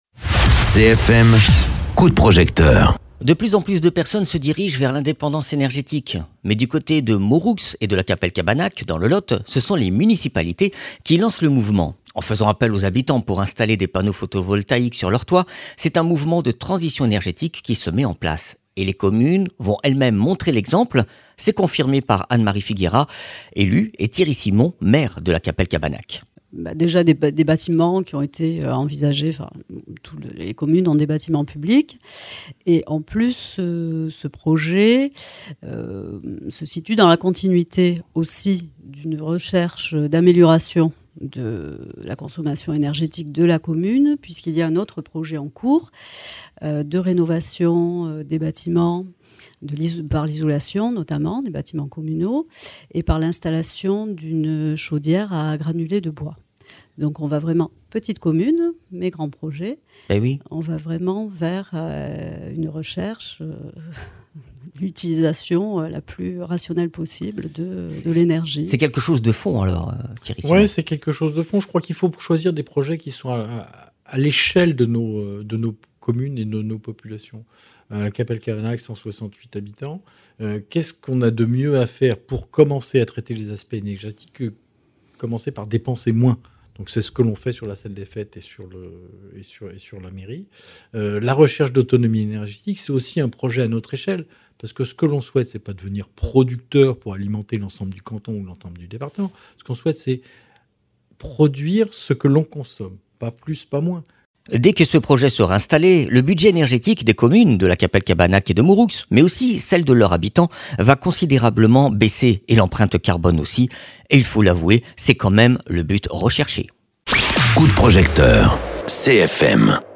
Interviews
Invité(s) : Anne-Marie Fighiera, elue et Thierry Simon, Maire de Lacapelle-Cabanac